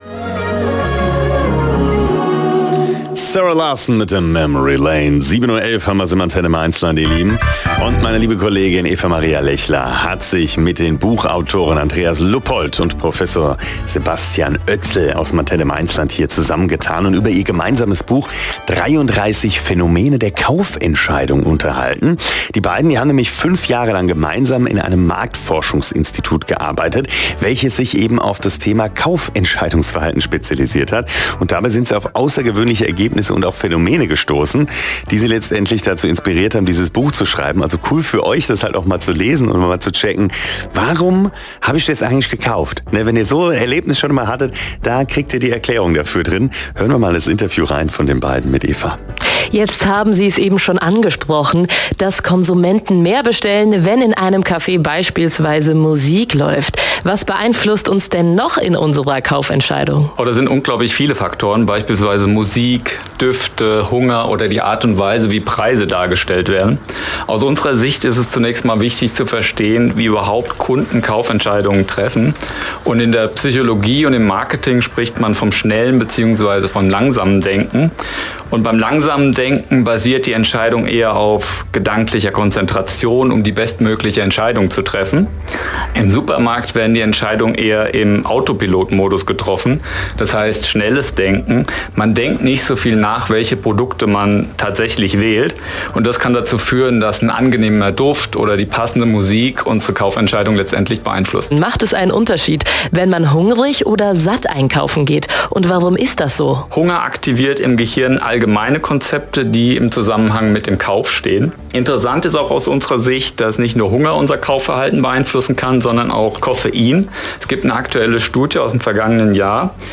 Mitschnitt der Sendung: